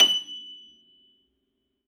53f-pno24-F5.aif